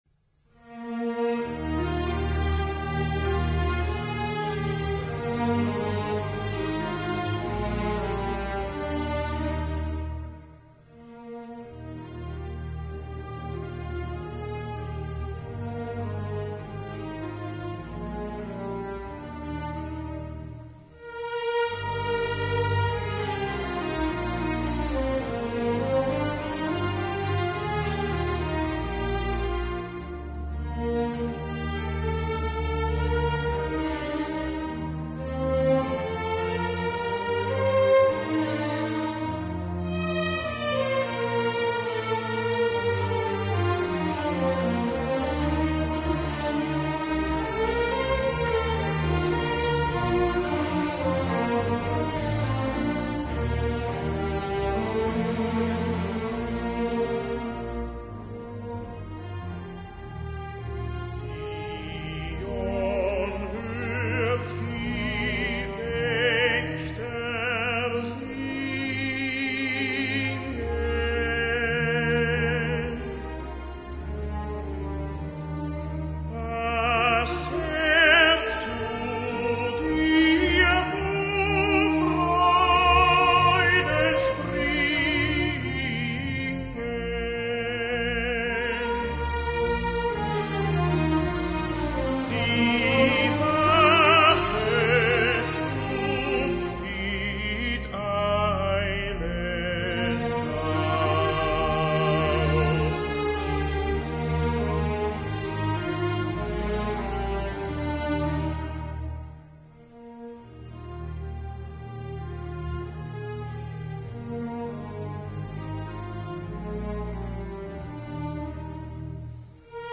Choral (Tenor)